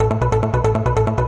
描述：合成器
Tag: 合成器